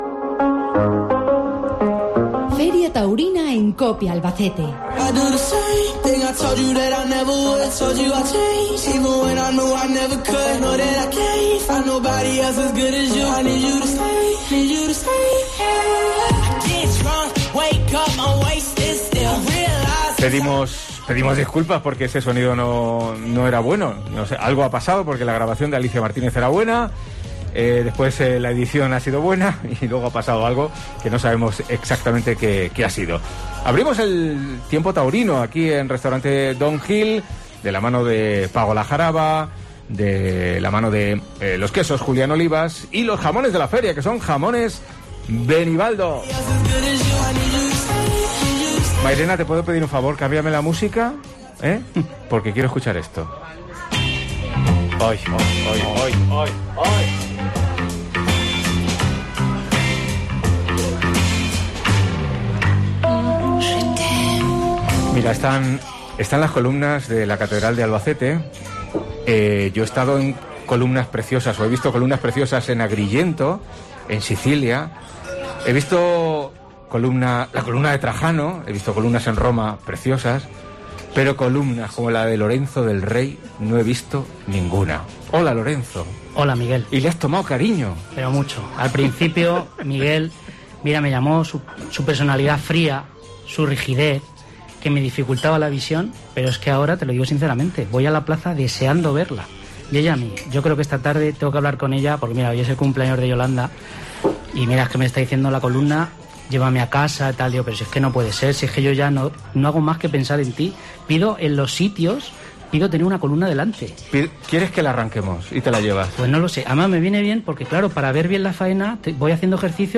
Tertulia taurina en COPE con brindis Pago La Jaraba